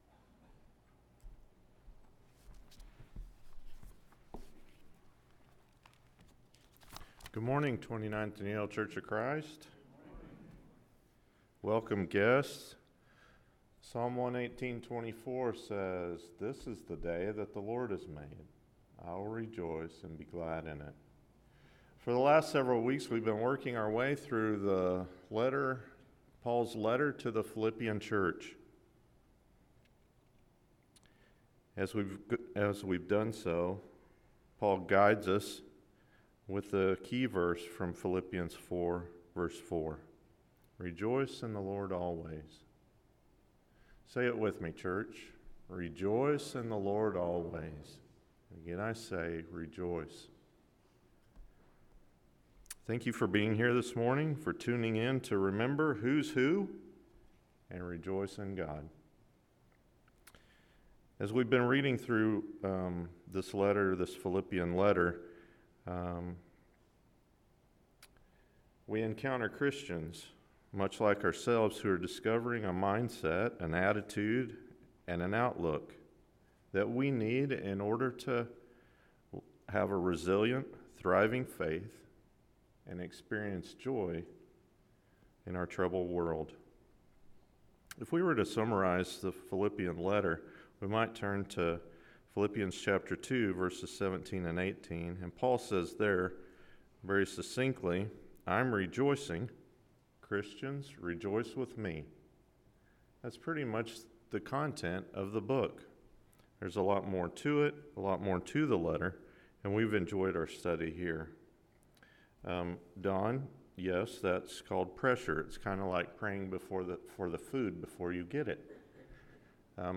Partners in the Gospel – Philippians 4:21-23 – Sermon — Midtown Church of Christ